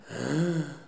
Les sons ont été découpés en morceaux exploitables. 2017-04-10 17:58:57 +02:00 154 KiB Raw History
ah-raisonnement_01.wav